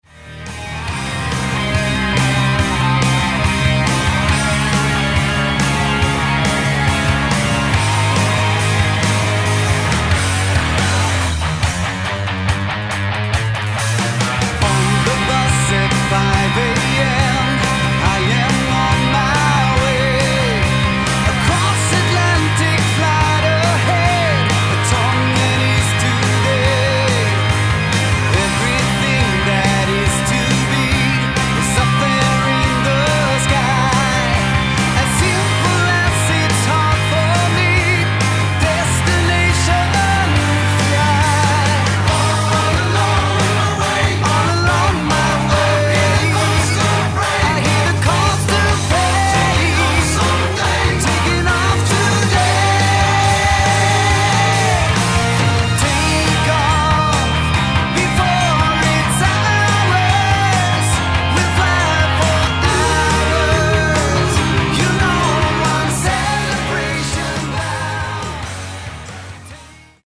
Guitars and Vocals
Bass and Vocals
Drums
Keyboards